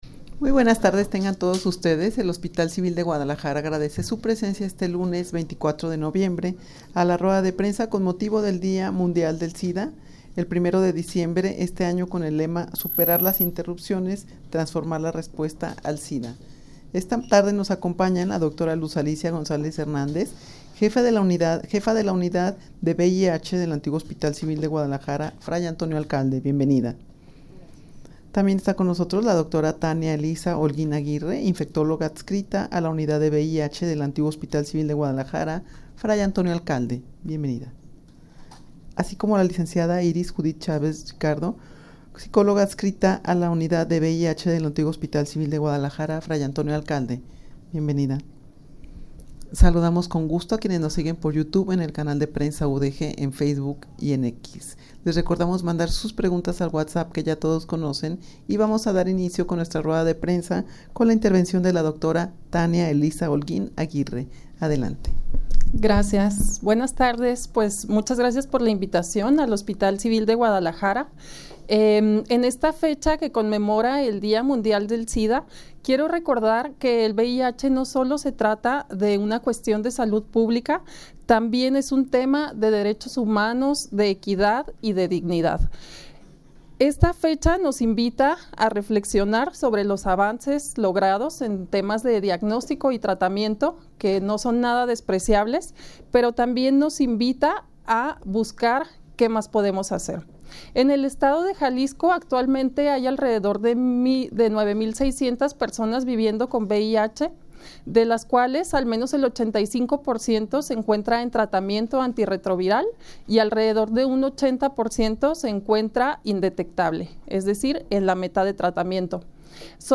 Rueda de prensa con motivo del Día Mundial del Sida.mp3